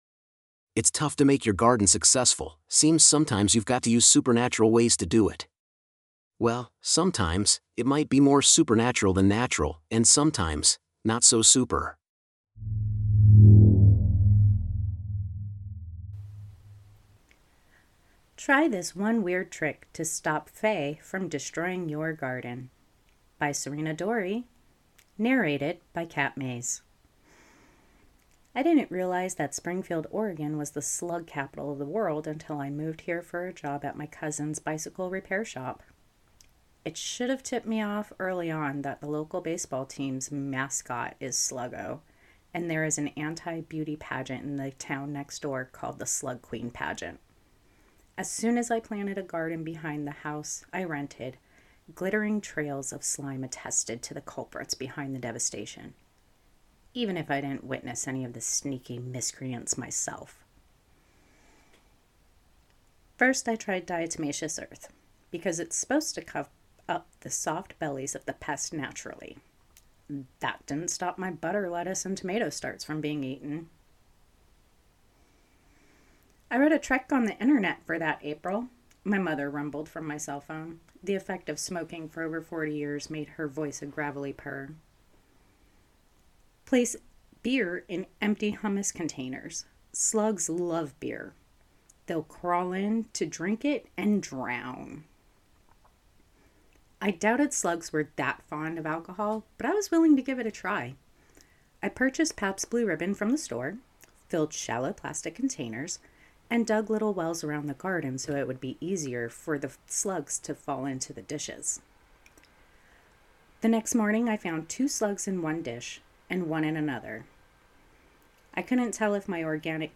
Try_This_One_Trick-ForWeb_Narrated.mp3